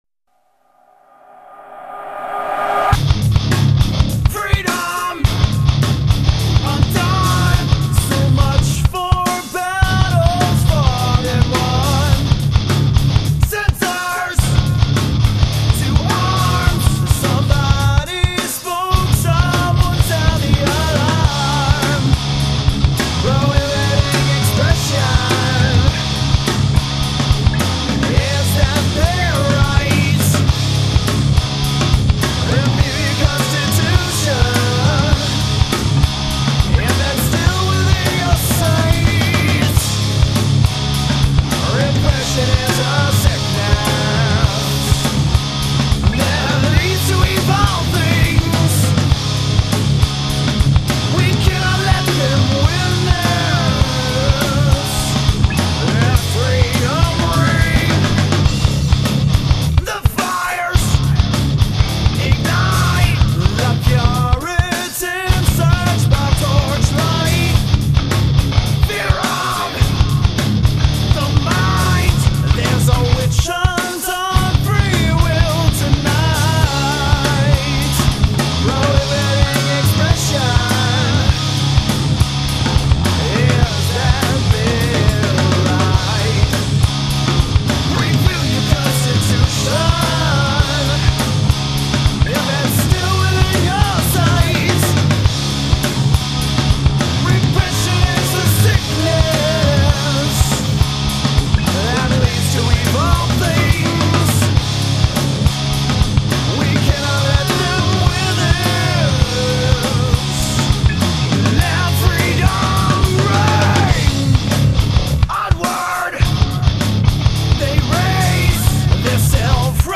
heavy song, straightforward and excellent
Guitar
Vocals
Drums
Bass